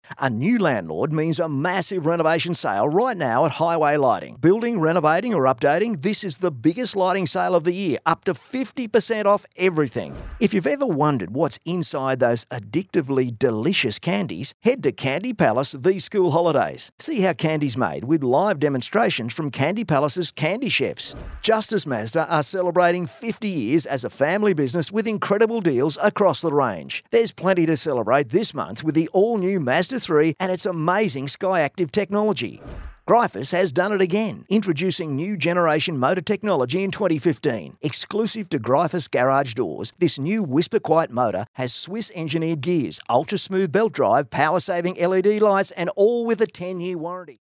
But pretty much specialises in the laid back Aussie style, relaxed , real and believable!
• Hard Sell
• Natural Aussie Bloke